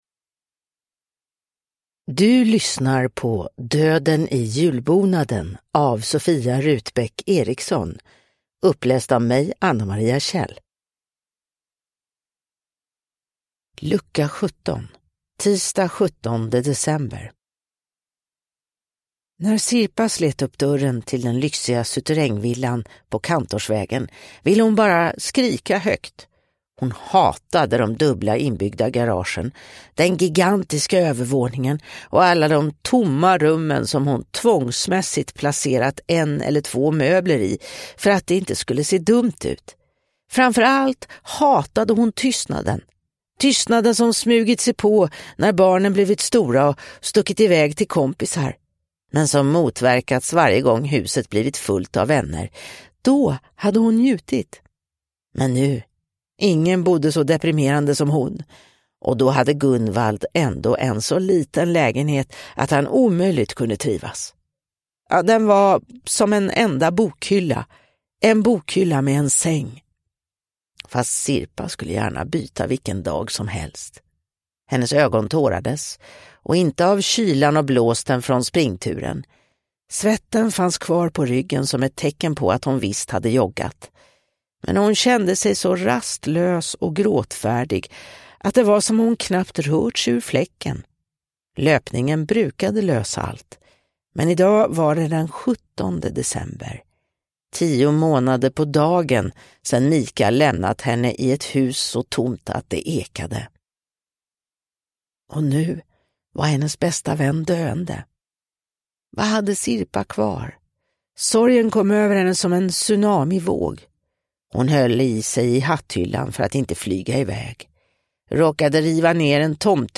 Döden i julbonaden: Lucka 17 – Ljudbok